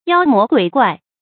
注音：ㄧㄠ ㄇㄛˊ ㄍㄨㄟˇ ㄍㄨㄞˋ
妖魔鬼怪的讀法